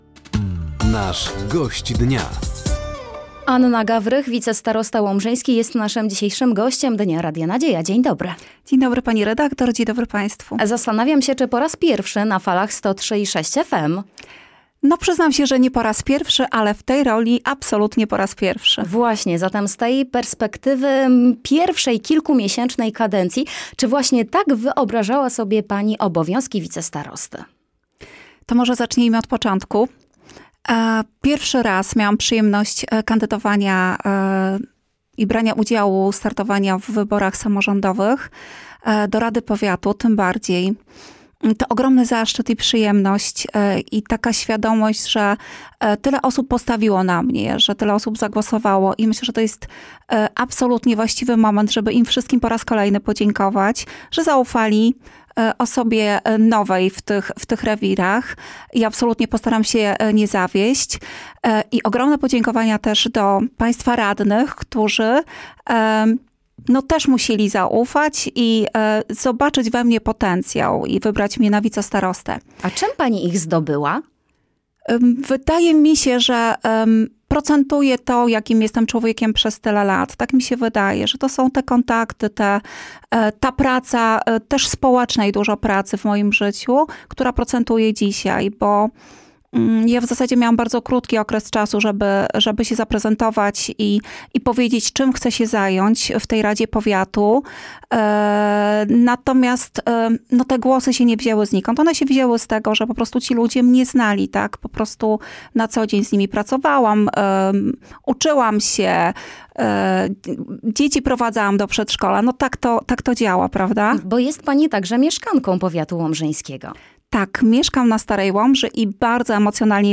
O relacjach po objęciu nowej funkcji, planach rozwoju regionu, a także oczekiwaniach mieszkańców mówiła podczas dzisiejszej audycji ,,Gość Dnia” Anna Gawrych, Wicestarosta Łomżyński.